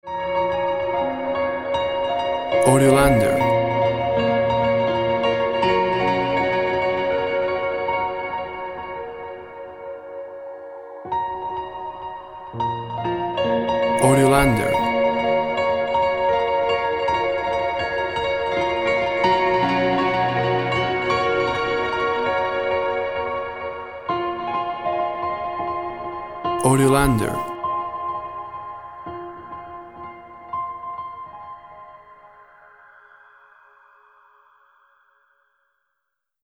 Reverb piano for seaside or space.
Tempo (BPM) 68